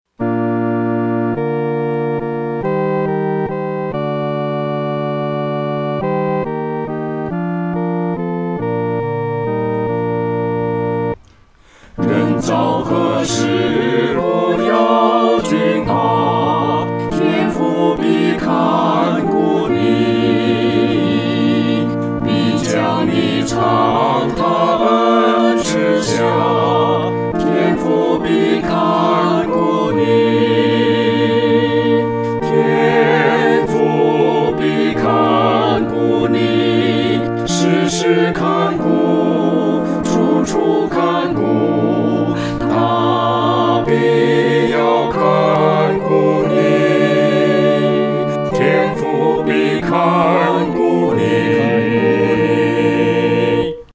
合唱（四声部）
天父必看顾你-合唱（四声部）.mp3